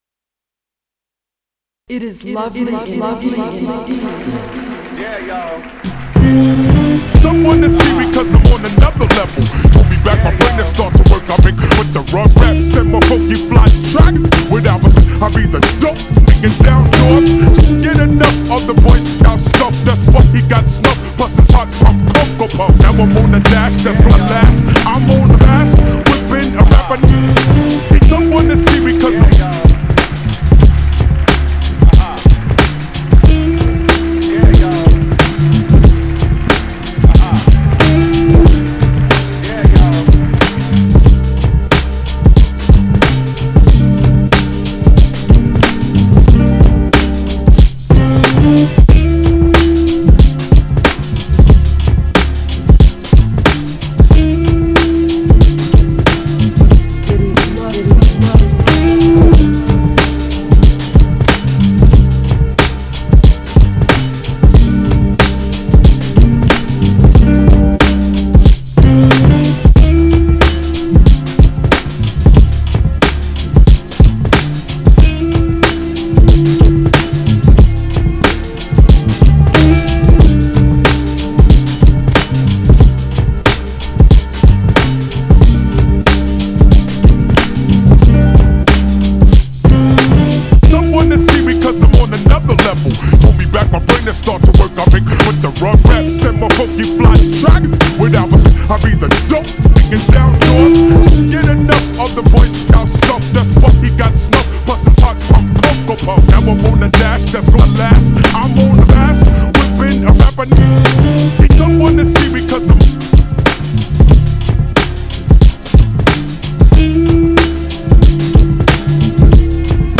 Lovley and deep